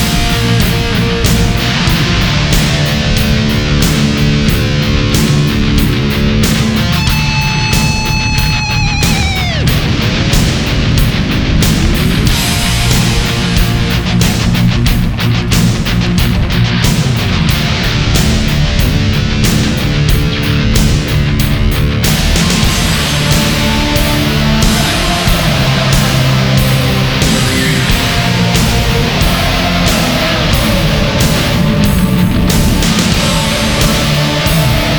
Жанр: Рок / Метал